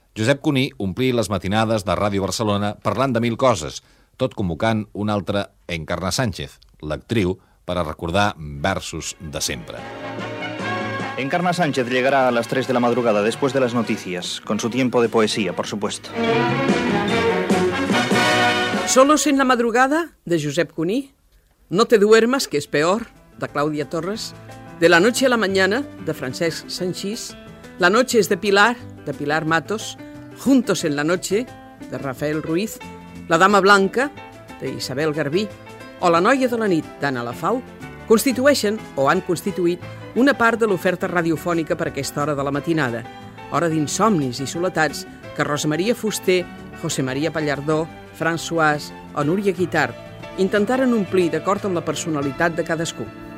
Els programes de ràdio de nit dels anys 80. Amb un fragment de "Sólos en la madrugada" de Josep Cuní a Ràdio Barcelona.
Divulgació
FM